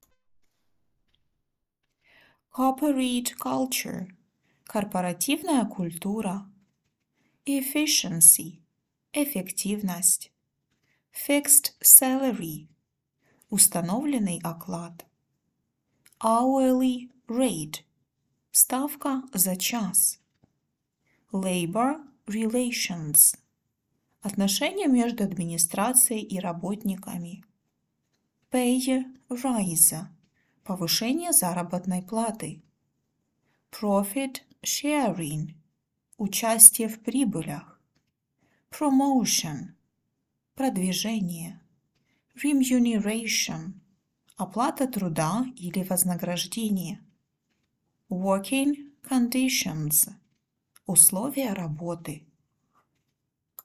• прослушивайте озвученные английские фразы ежедневно, повторяйте за диктором сначала английский вариант, а затем и русский перевод;